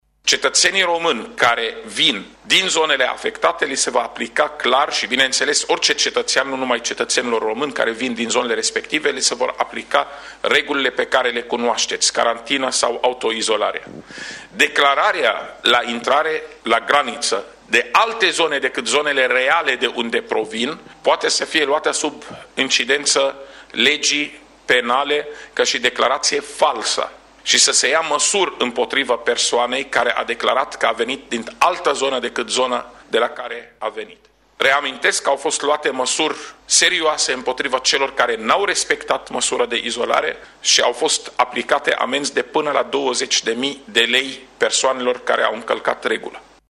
La sediul Ministerului de interne au fost anunțate ai noi măsuri de luptă împotriva coronavirusului.
Șeful Departamentului pentru Situații de Urgență, Raed Arafat, a anunțat că s-a dispus luarea măsurii de interzicere a oricăror activități care presupun participarea unui număr mai mare de 1.000 de persoane.